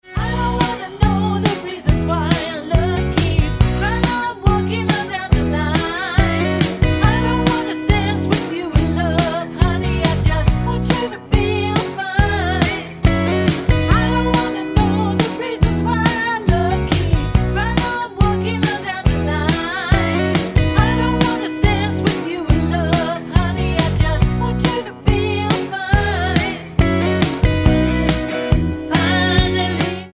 --> MP3 Demo abspielen...
Tonart:B mit Female Lead mit Chor